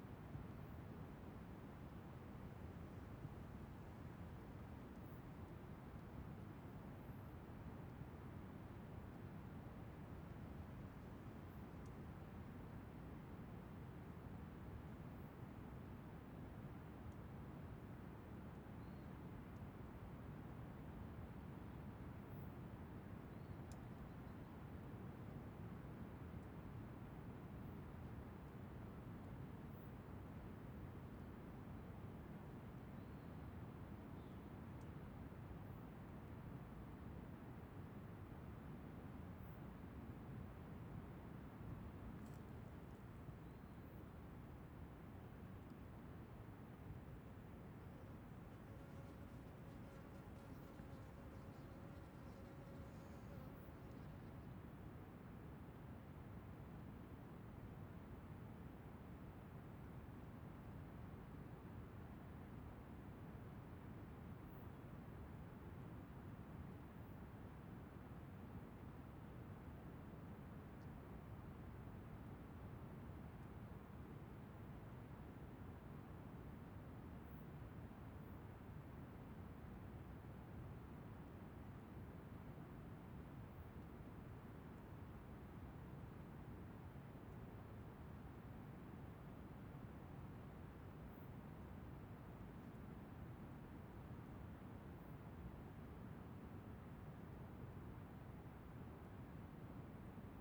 Ambiencia Mata Aberta com eixo do Microfone de costas para Cachoeira
Parque Nacional da Chapada dos Veadeiros , Vento
Stereo
CSC-05-014-GV - Ambiencia Mata Aberta com eixo do Microfone de costas para Cachoeira.wav